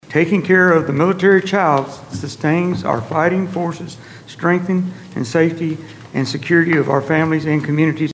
Christian County Fiscal Court opened Tuesday’s meeting celebrating “Month of the Military Child,” while proclaiming Wednesday, April 15, a “Purple Up Day” across the community.